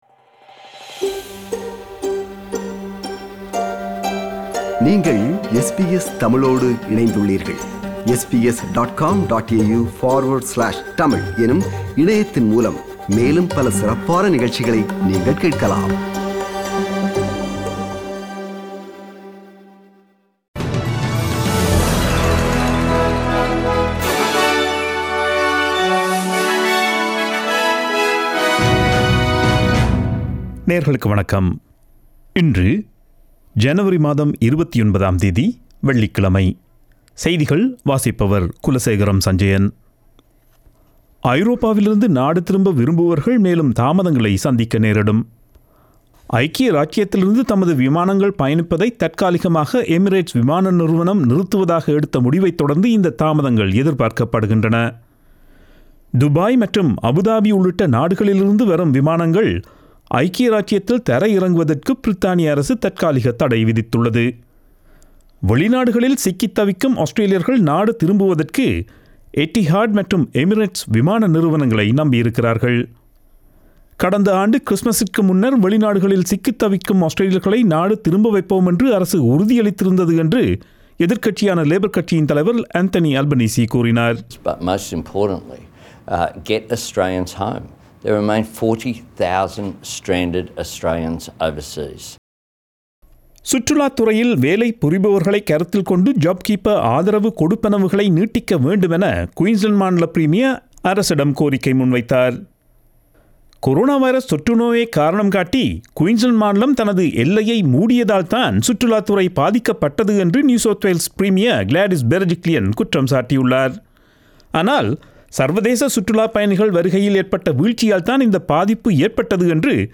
Australian news bulletin for Friday 29 January 2021.